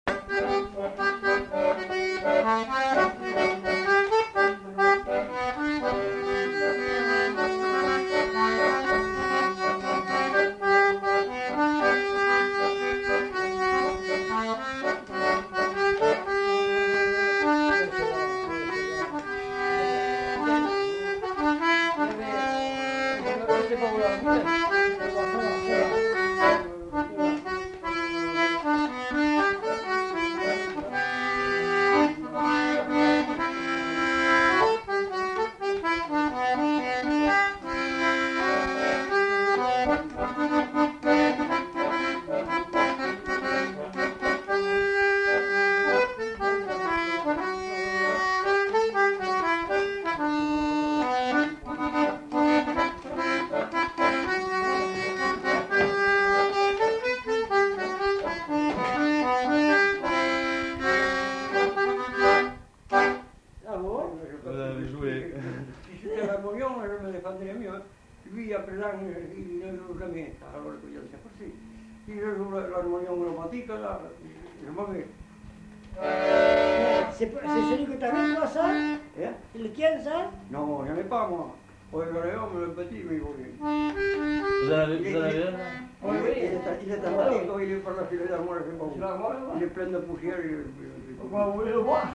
Répertoire d'airs à danser du Marmandais à l'accordéon diatonique
enquêtes sonores
Valse